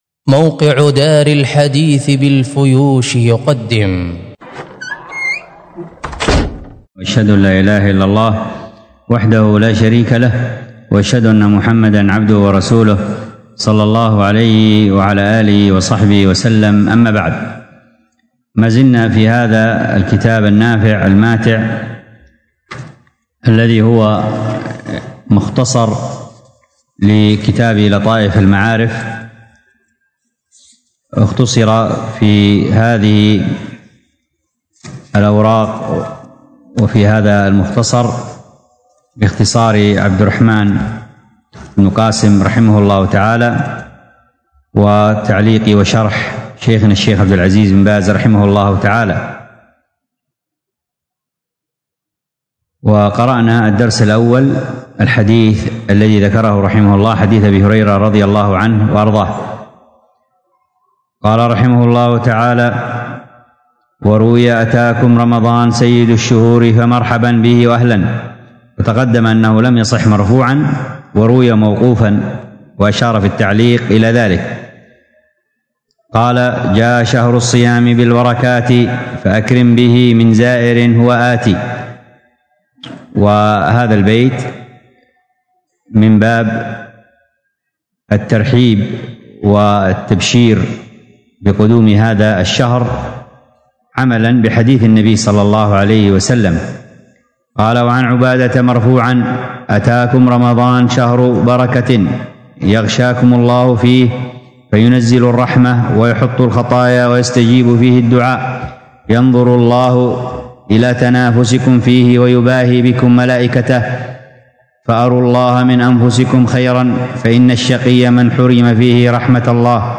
الدرس في كتاب الطهارة 31، ألقاها